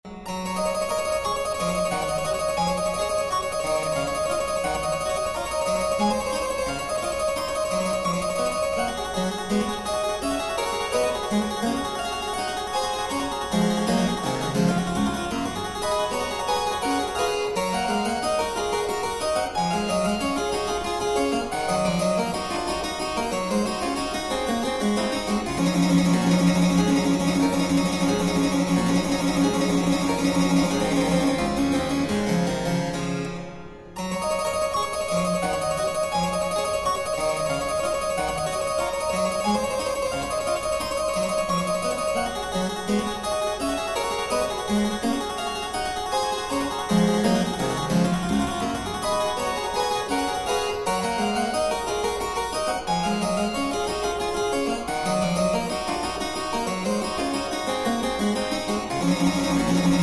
solo harpsichord work
Harpsichord